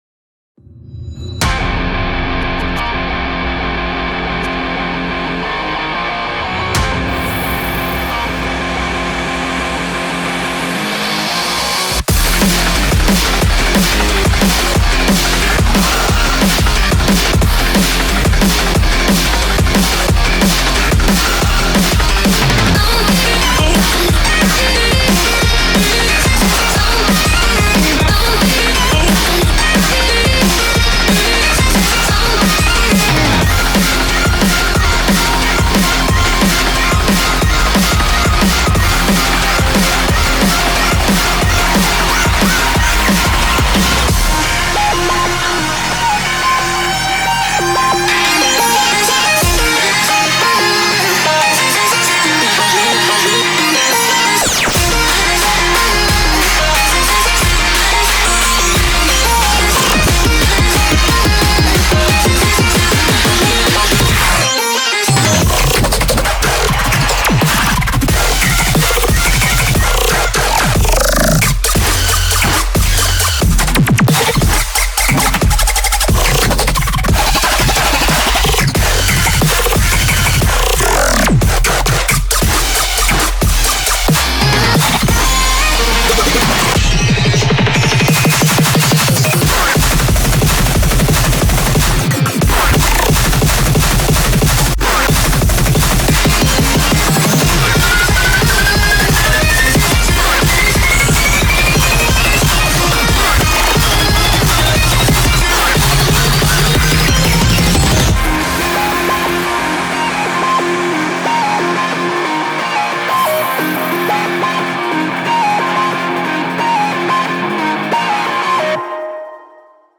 BPM180-360
Audio QualityPerfect (High Quality)
Commentaires[DRUMSTEP]